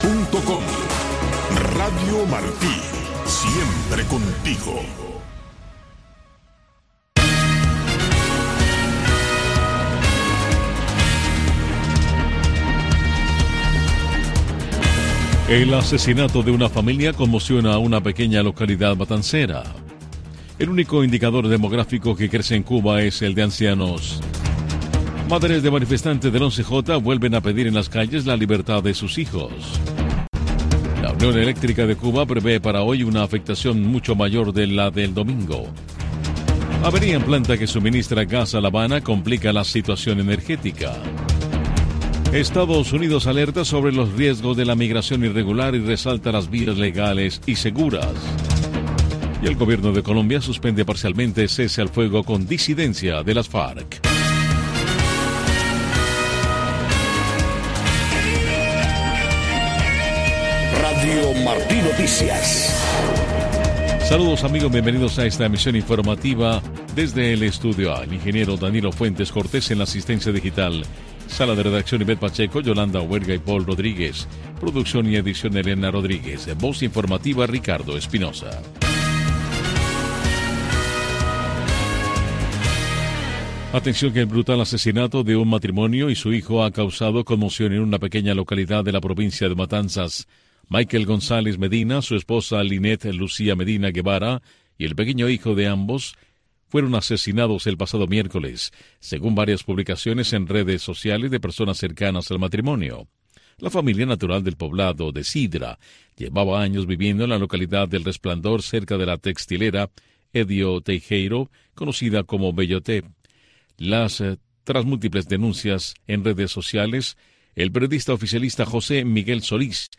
Noticiero de Radio Martí 3:00 PM